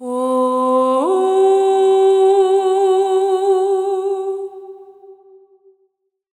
MZ Vox [Drill Intervals].wav